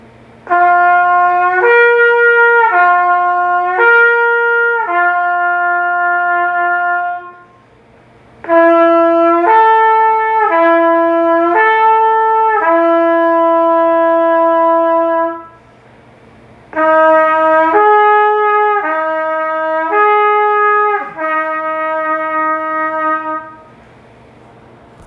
音の変わり目に段差があるのがわかるでしょうか？これは滑らかに音が変われていない例です。
【音の変わり目に段差がある】
リップスラー間違い-C01.wav